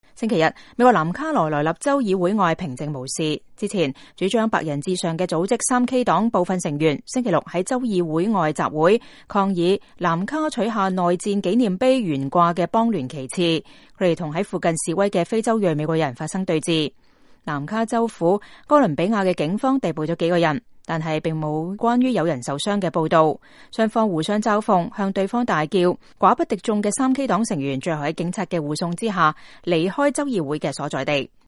抗議取下邦聯旗幟的3K黨示威者與支持摘旗者相互吶喊